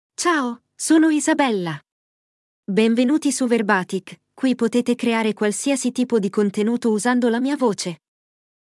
FemaleItalian (Italy)
Voice sample
Female
Isabella delivers clear pronunciation with authentic Italy Italian intonation, making your content sound professionally produced.